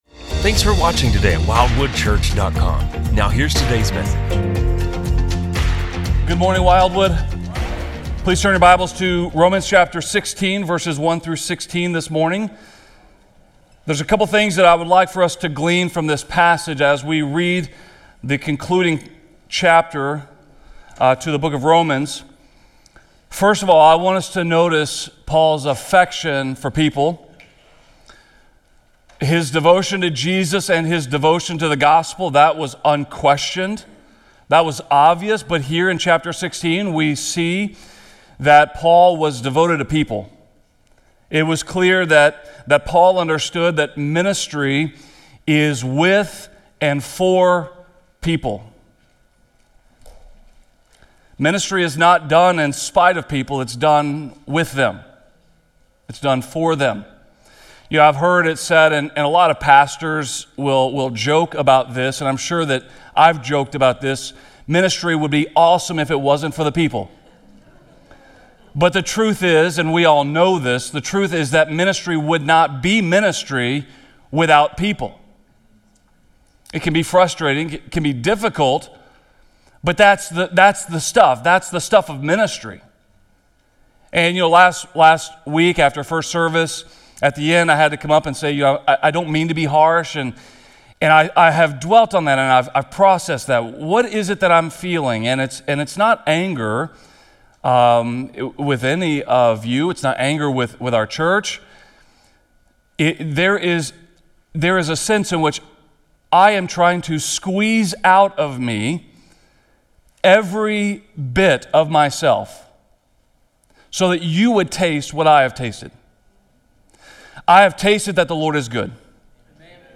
This sermon challenges us to live in such a way that when our names are spoken, they are remembered for love, service, and a life poured out for the glory of God.